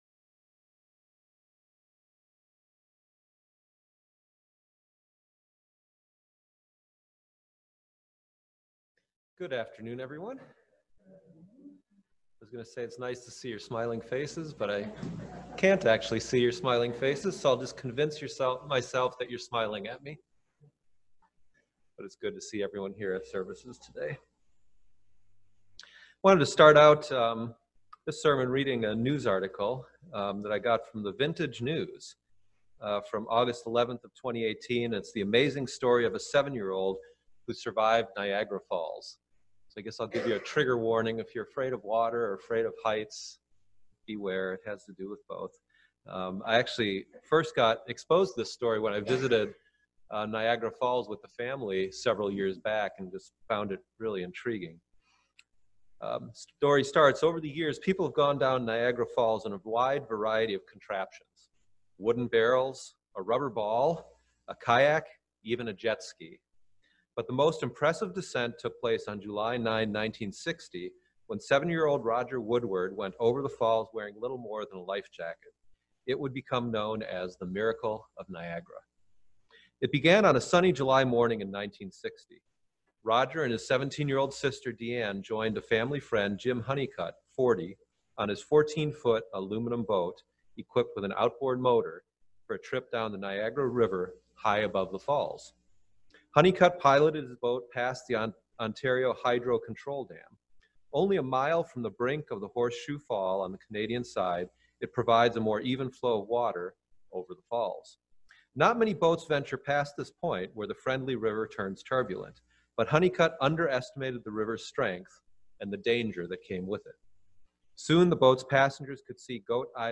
I wanted to start out this sermon reading a news article that I got from The Vintage News from August 11th of 2018.